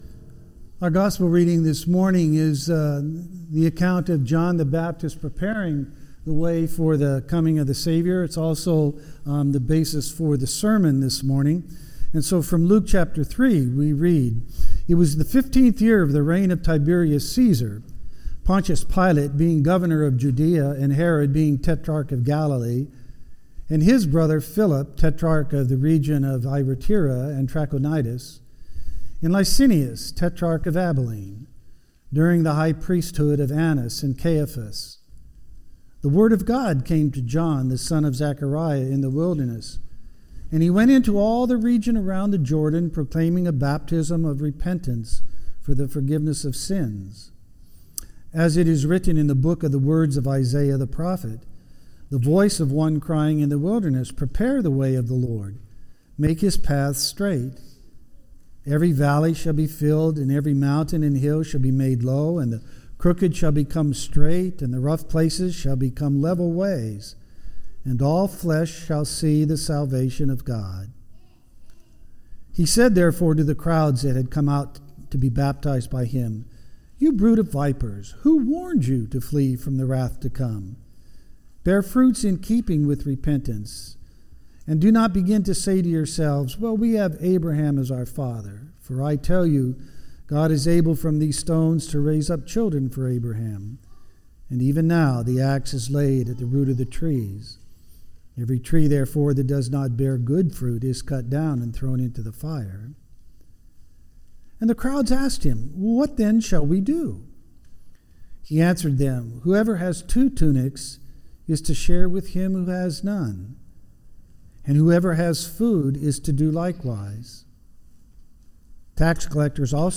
- Christ Lutheran Church HB, CA